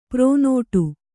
♪ pronōṭu